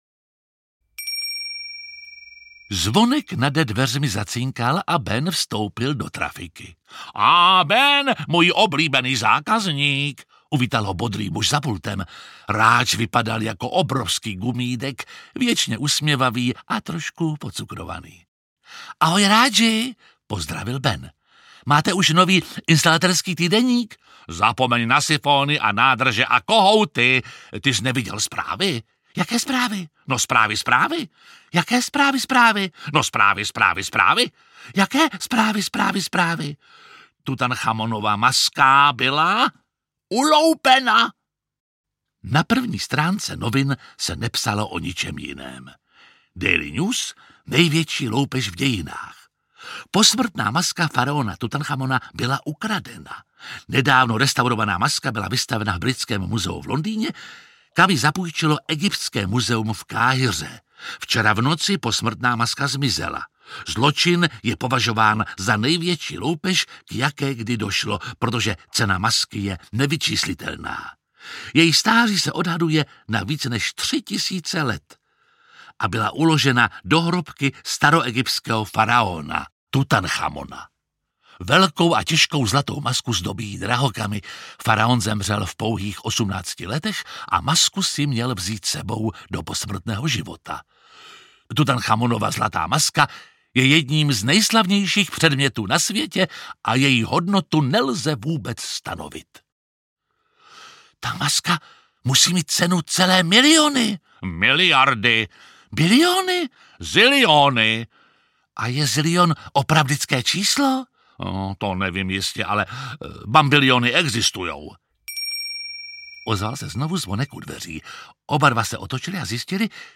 Ukázka z knihy
Čte Jiří Lábus.
Vyrobilo studio Soundguru.
• InterpretJiří Lábus